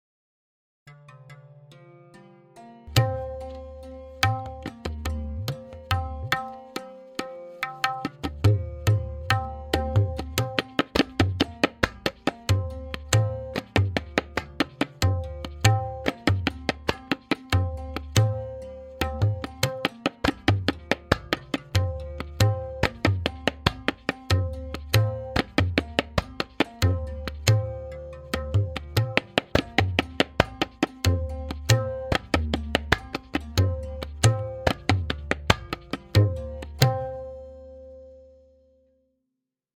Note: The following forms are all played in dugun (2:1).
Simple Chakradar Variation 1 with Theka and Lahra
M8.5-SimpleCK-V1-Theka-Lehra.mp3